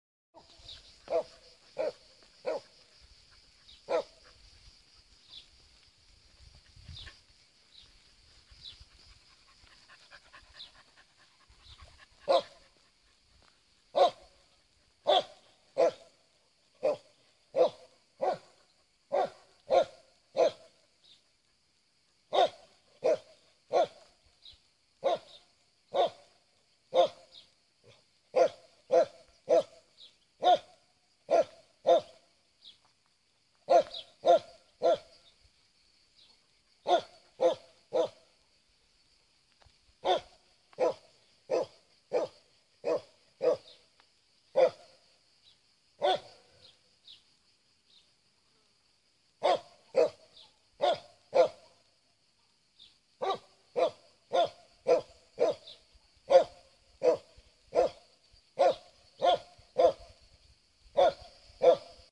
标签： 狂吠 咆哮 宠物